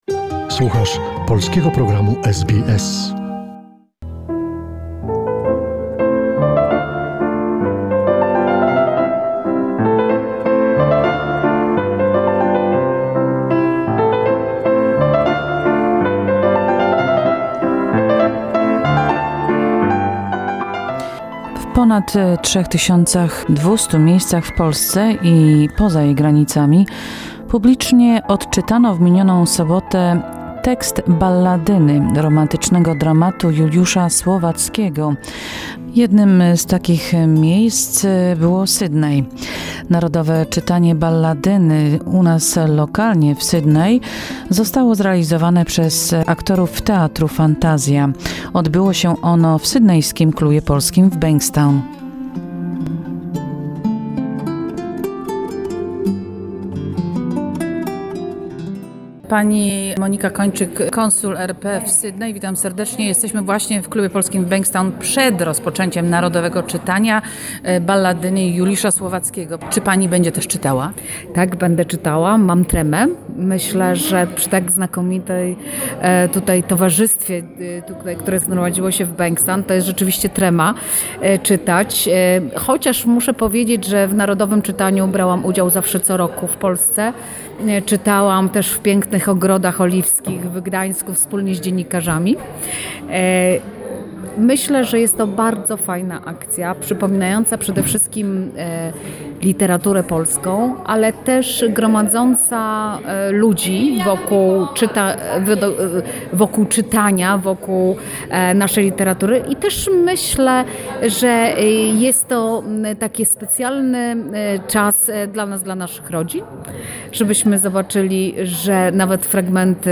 On Saturday, September 5, in Sydney at the Polish Club in Bankstown, a national reading of Juliusz Słowacki's 'Balladyna' was performed by Theatre Fantazja.